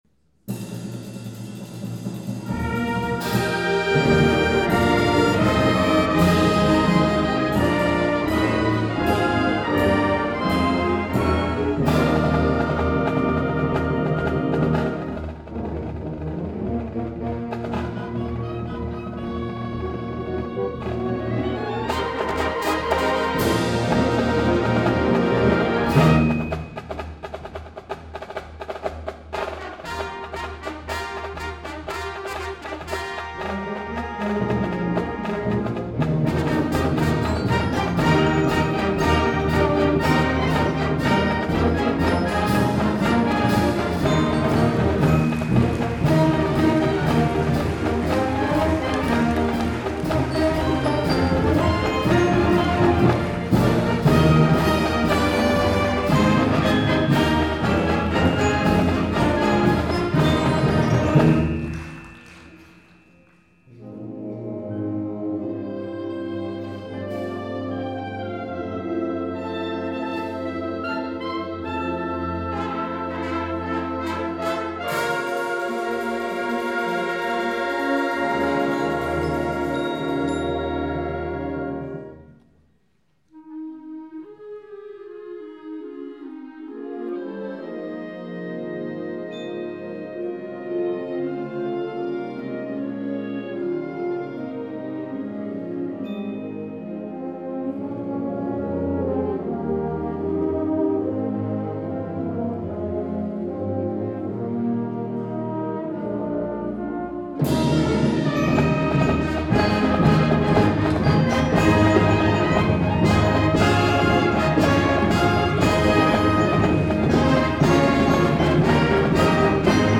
2012 Summer Concert